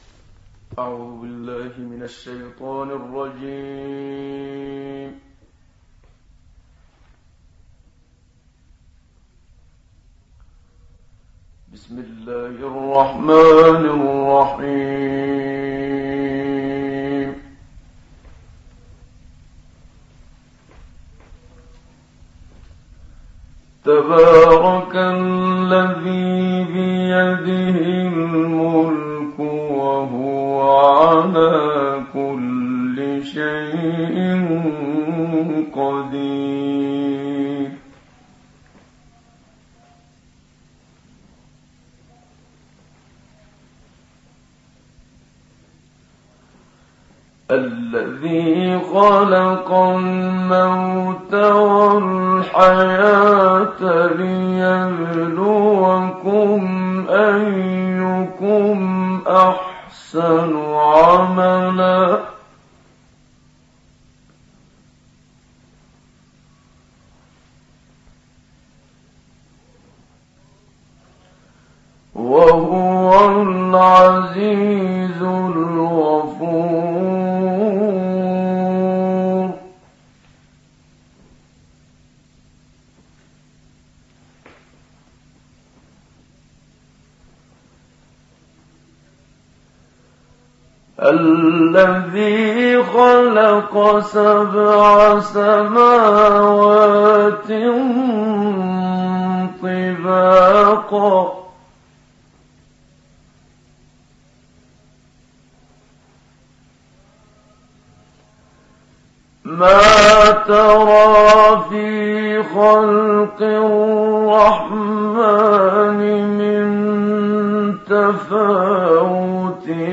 تلاوات ستوديو إذاعة دمشق - خمسينات للشيخ محمد صديق المنشاوي